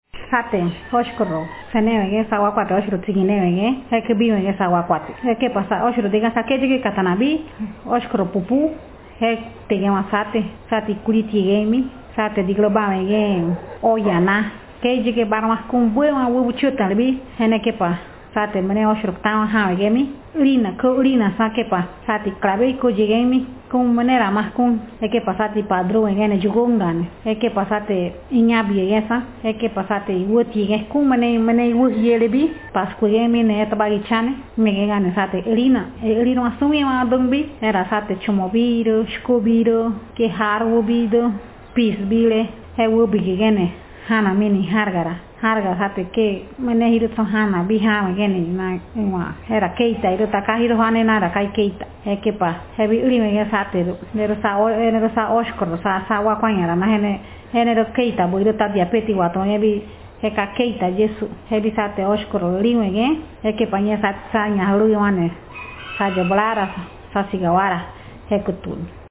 Speaker sexf
Text genreprocedural